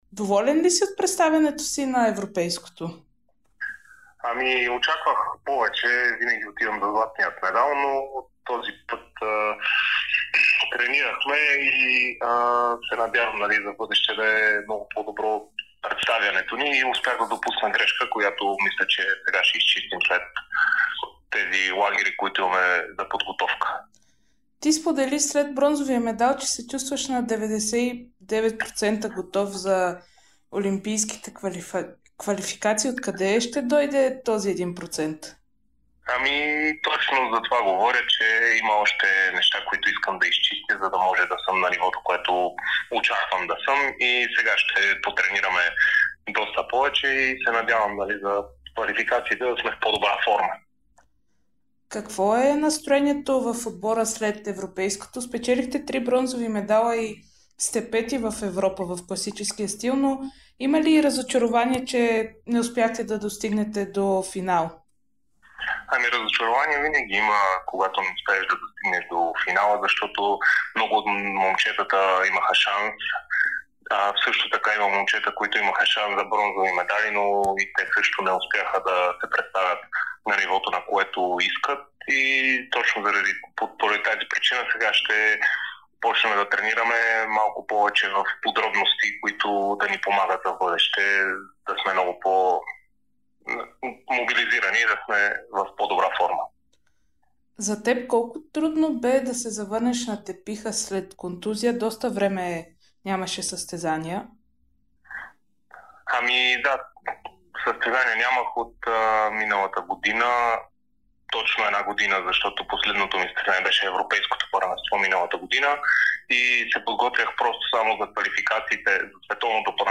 Кирил Милов даде специално интервю за Дарик радио и dsport след бронзовия си медал на Европейското първенство по борба.